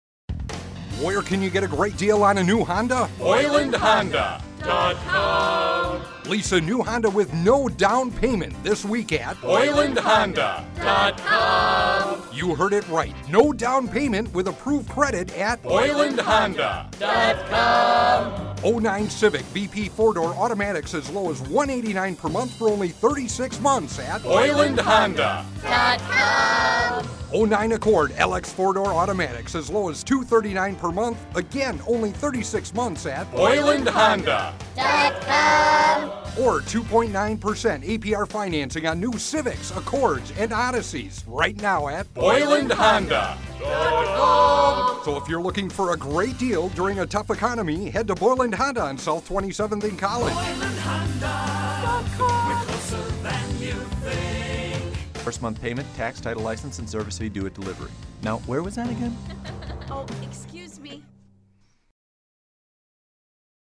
Boyland Honda .Com Radio Commercial